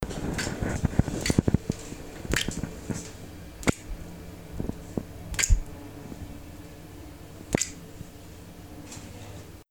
Diferentes goteos de agua
Grabación sonora del sonido de diferentes goteos de agua. Sonidos cotidianos
goteo
Sonidos: Agua